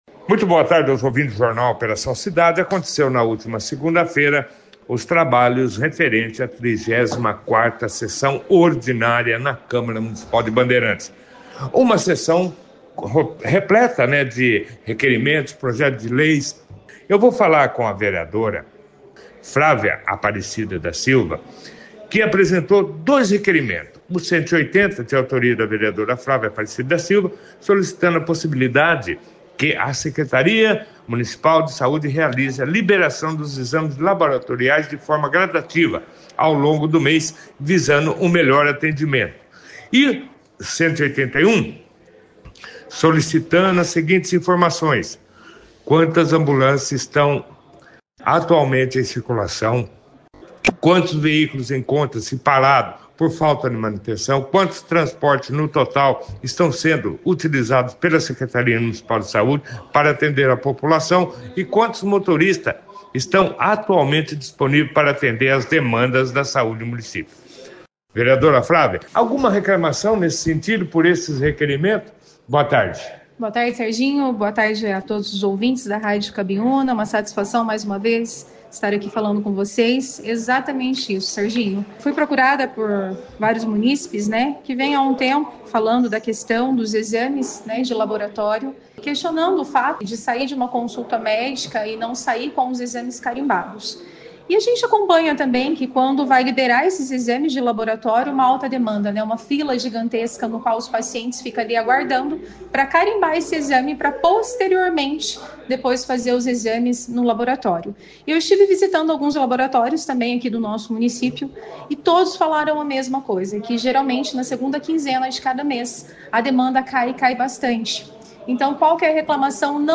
Realizada na última segunda-feira, 3 de novembro, a 34ª Sessão Ordinária da Câmara Municipal de Bandeirantes foi destaque no jornal Operação Cidade, desta terça-feira, 4 de novembro, com a participação dos vereadores e de representantes do transporte escolar.